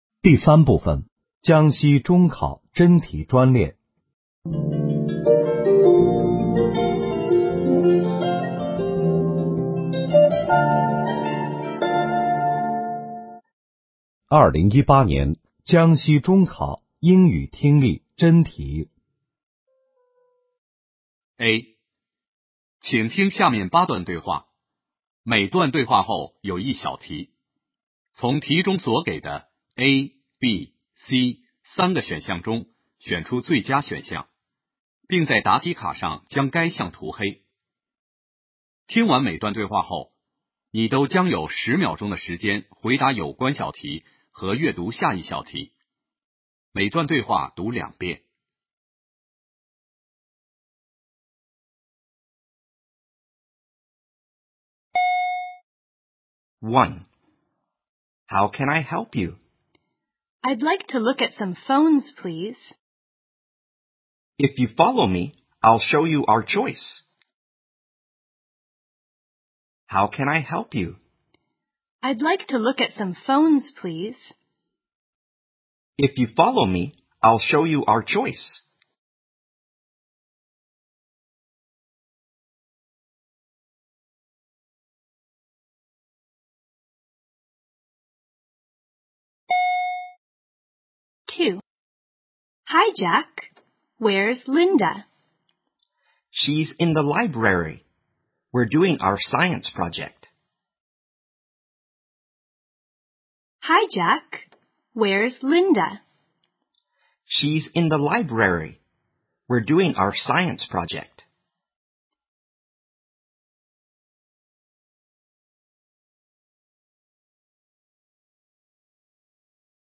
2018年江西中考英语听力真题：